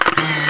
camera_click.wav